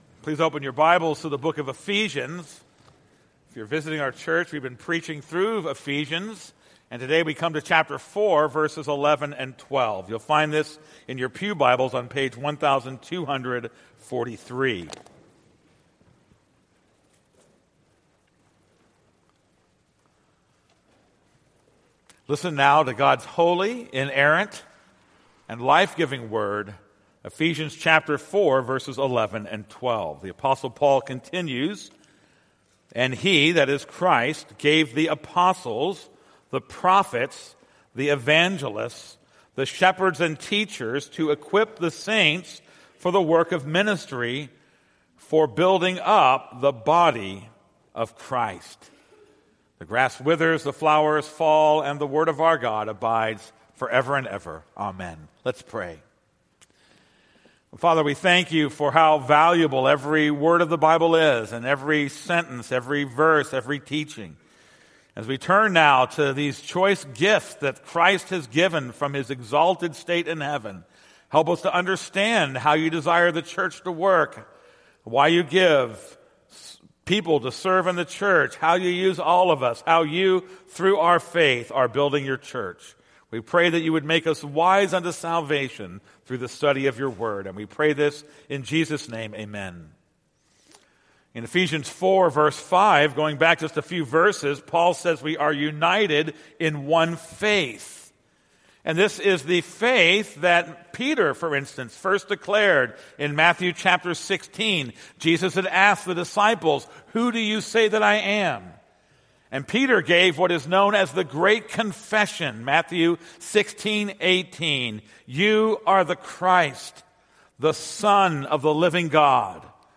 This is a sermon on Ephesians 4:11-12.